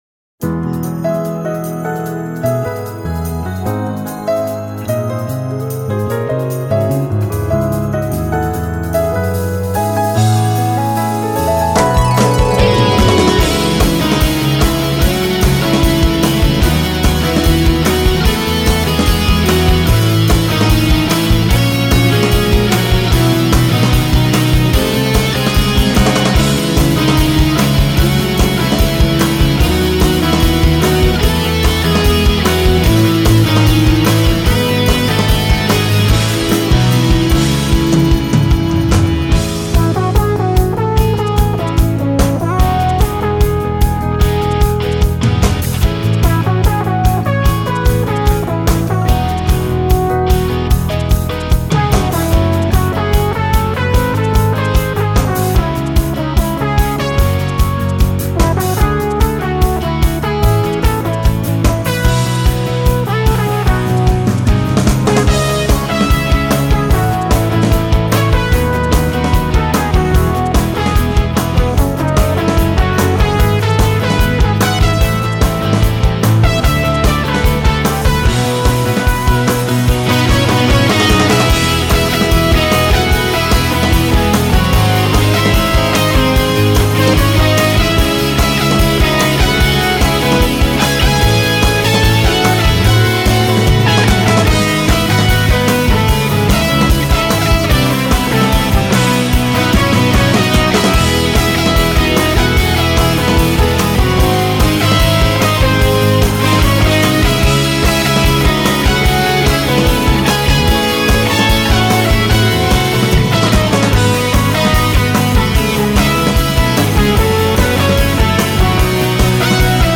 전체적인 앨범은 패션플라워와 33을 섞어놓은 느낌입니다.